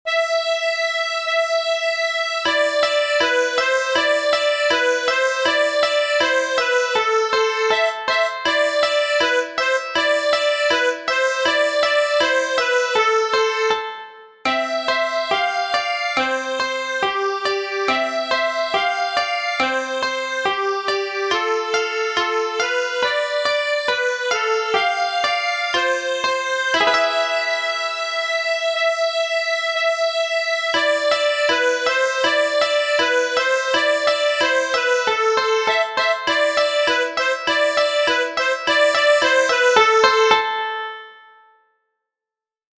Click on the words accordion and balalaika to learn something more about these instruments used in the score.
Sing the notes again but this time substitute the four semiquavers with a crotchet (use the note of the first semiquaver).